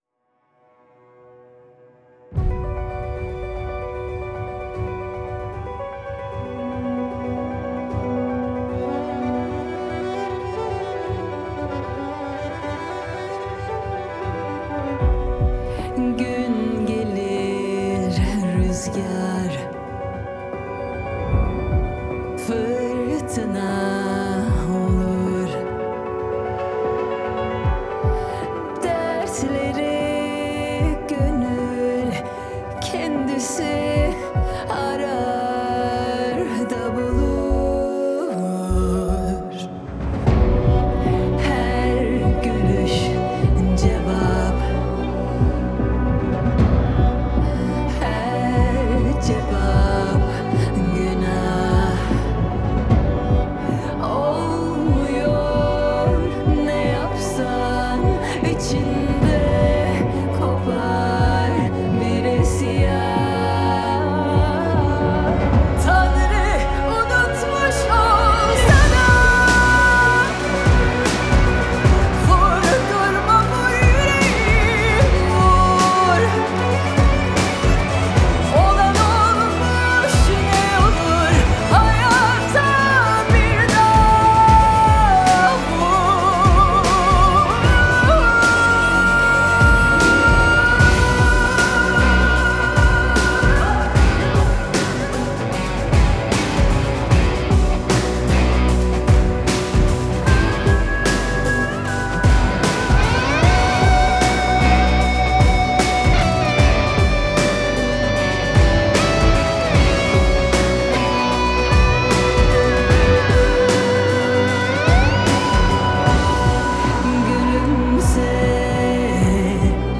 Трек размещён в разделе Турецкая музыка / Рок / 2022.